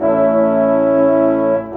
Rock-Pop 01 Brass 03.wav